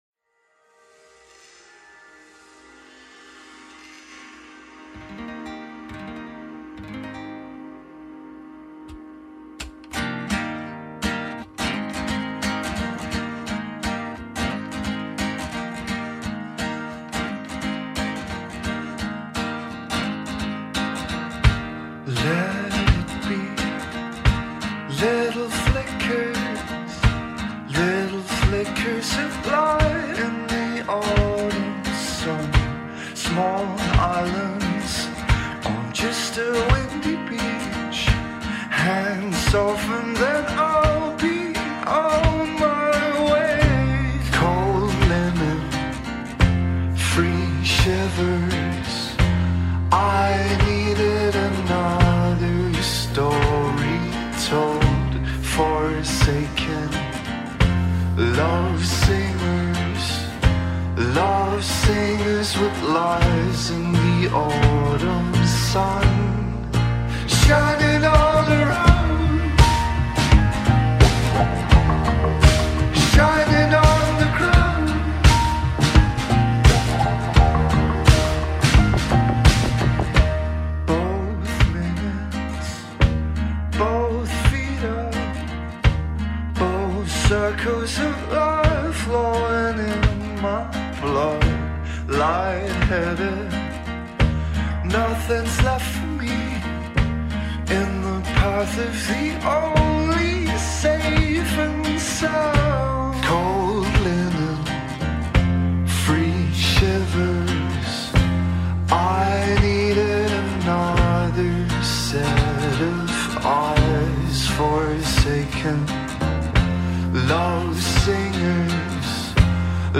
electronic ambient folk songs
was recorded in Aarhus, Denmark the year previous in 2014